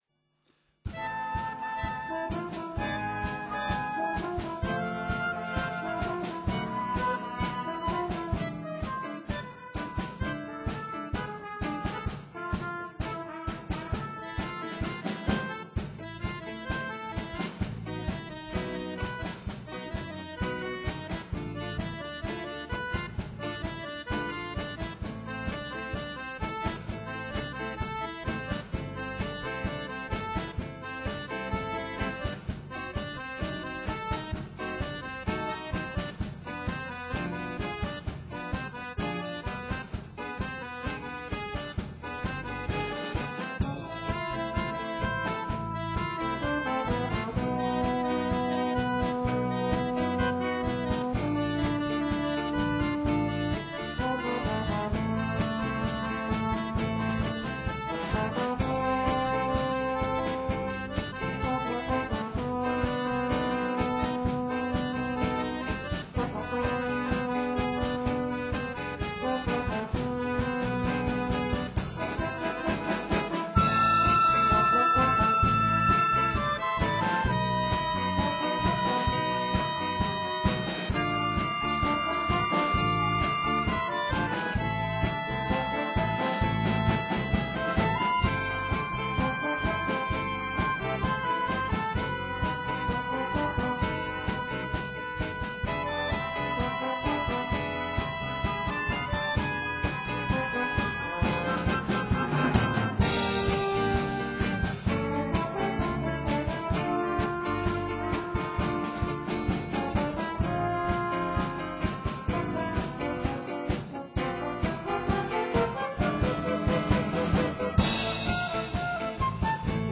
LA CHORALE DANSE
Concert du 21 et 22 mai 2015